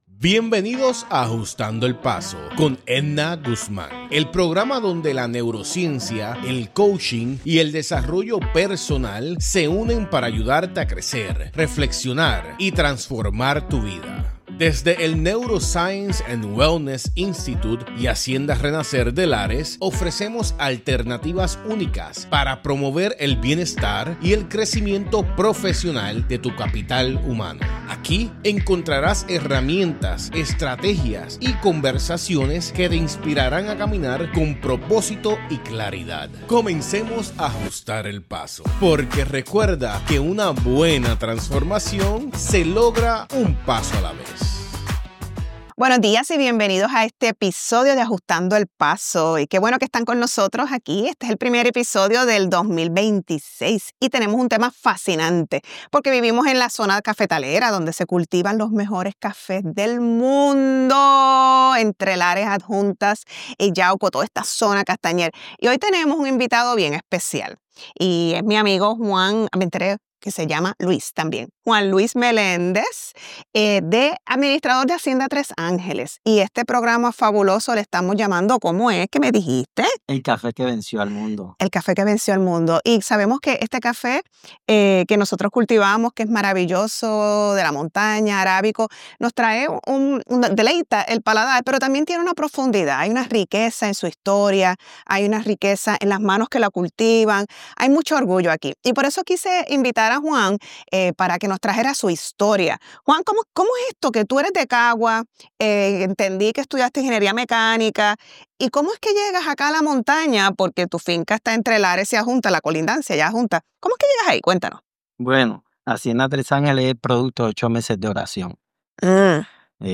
En este episodio entrevistamos